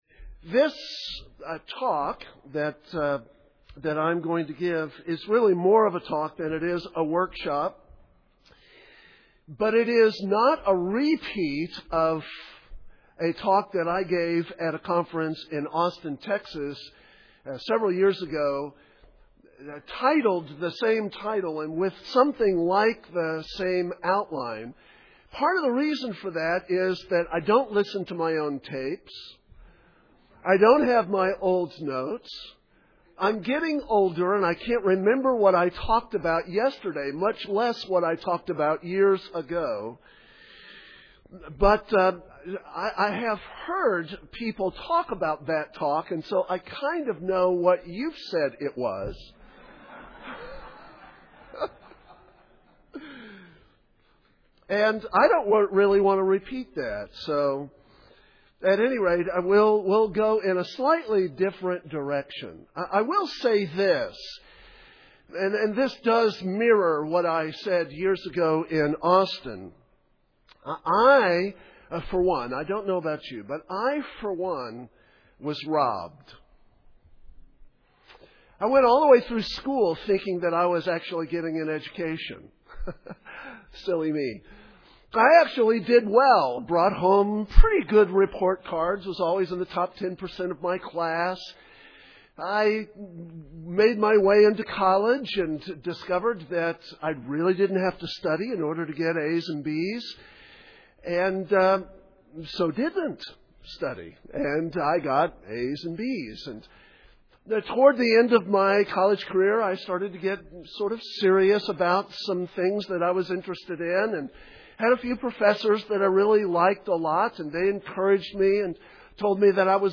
2007 Workshop Talk | 0:52:14 | All Grade Levels, Culture & Faith